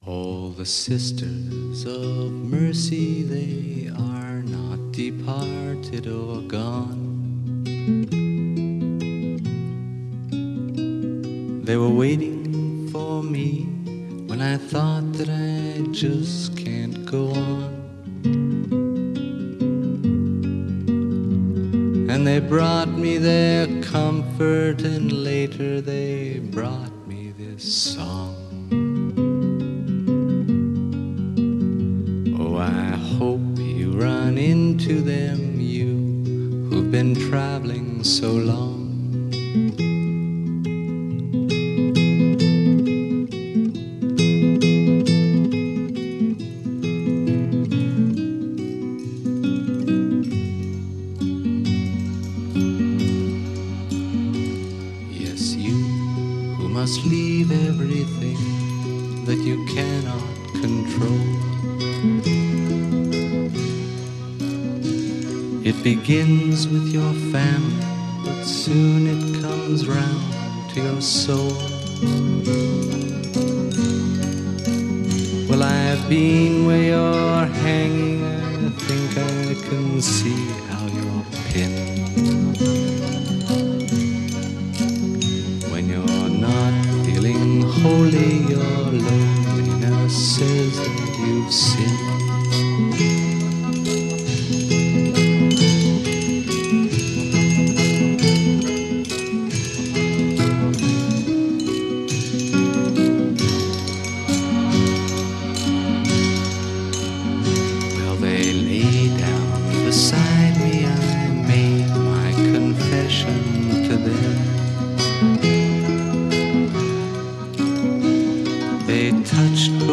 Фолк музыка